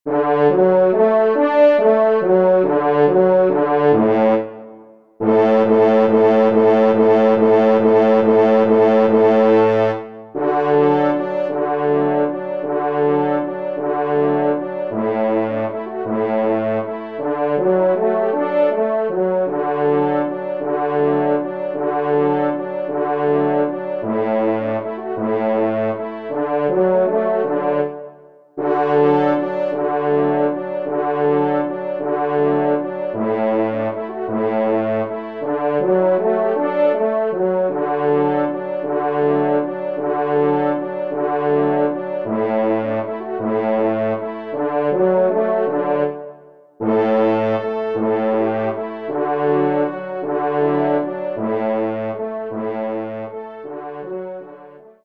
Valse
Genre :  Divertissement pour Trompes ou Cors en Ré (Valse)
5e Trompe